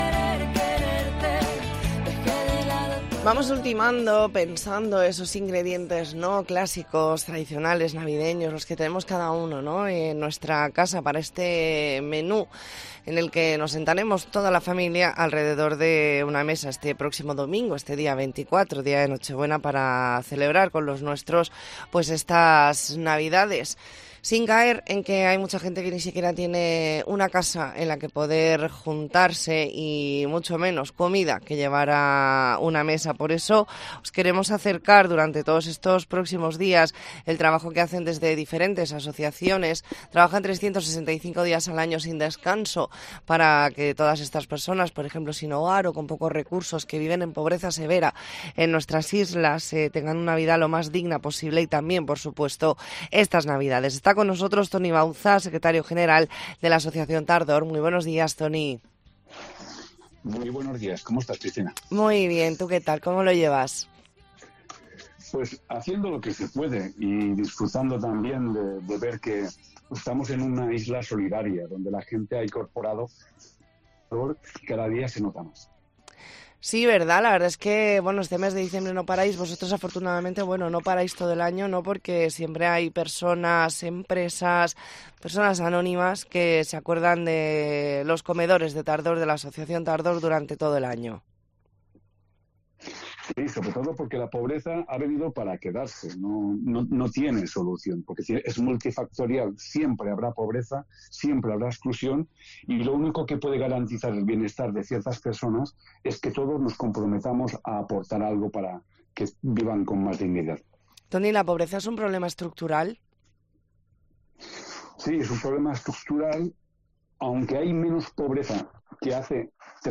Entrevista en La Mañana en COPE Más Mallorca, lunes 18 de diciembre de 2023.